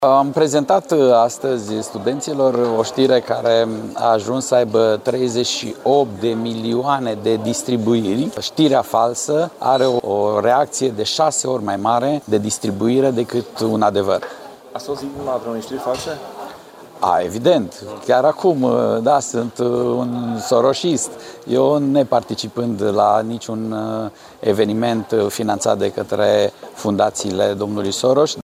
Peste 300 de personae, marea majoritate fiind formată din studenți, au participat la dezbaterea cu tema “Dezinformarea şi inteligenţa artificială – rolul UE în combaterea acestui fenomen” de la Universitatea “Aurel Vlaicu” din Arad.
Europarlamentarul Gheorghe Falcă a declarat că, în aceste zile, a devenit victima unor fake news.
1-Gheorghe-Falca-fake-news.mp3